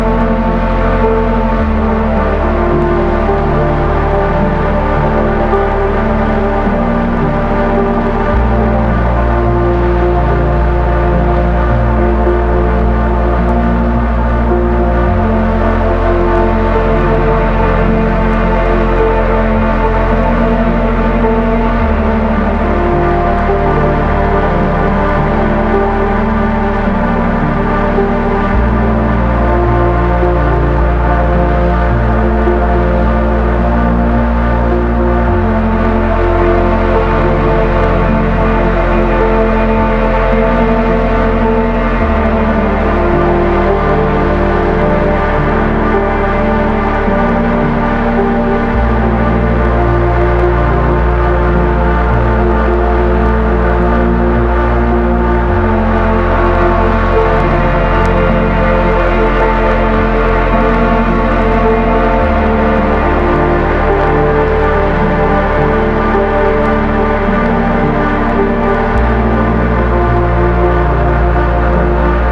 Ambient Electronica / Synth Soundscape